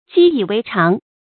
積以為常 注音： ㄐㄧ ㄧˇ ㄨㄟˊ ㄔㄤˊ 讀音讀法： 意思解釋： 指習慣成自然。